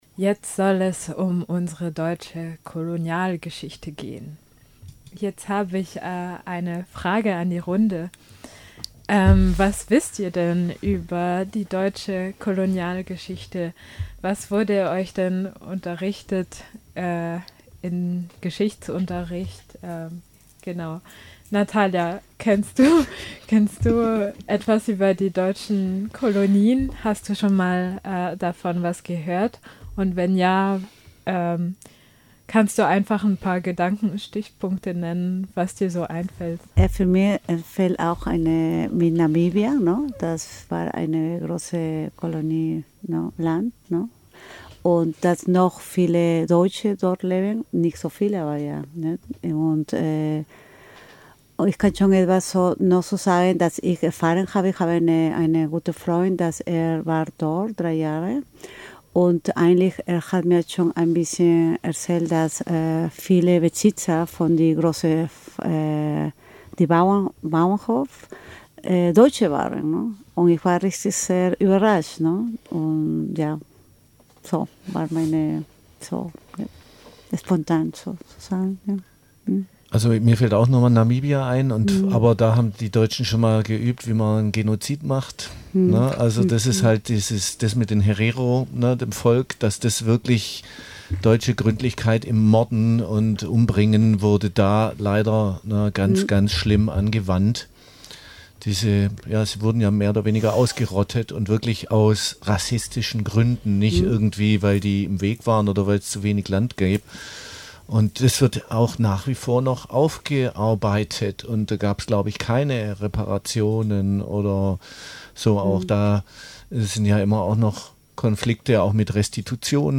72238_Diskussion_Kolonialgeschichte_komplett.mp3